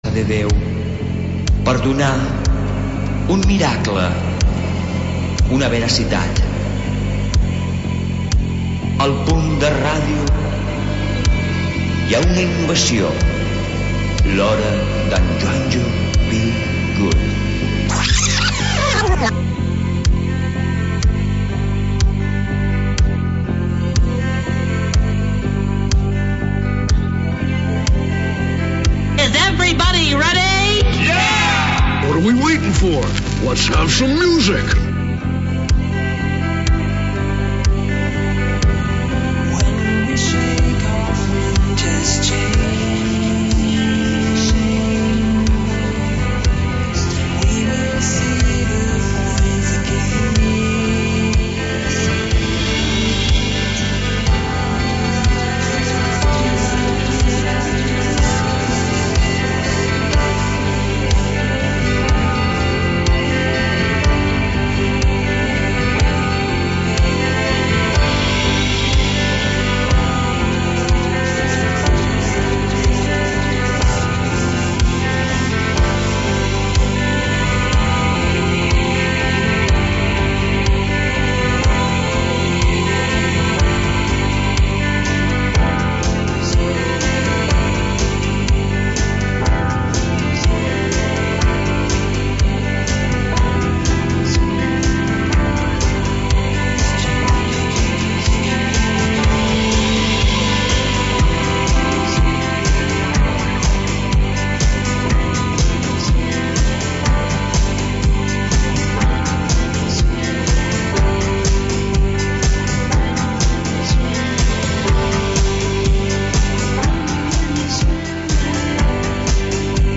Selecció musical independent